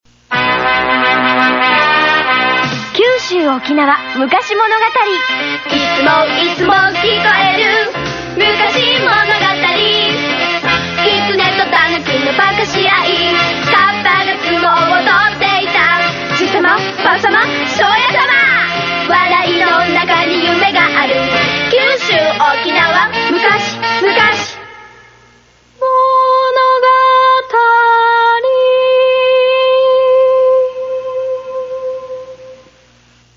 やっぱ何度聞いてもヘンな曲ですわ。